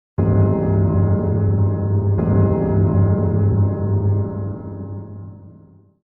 音效
地藏敲钟.mp3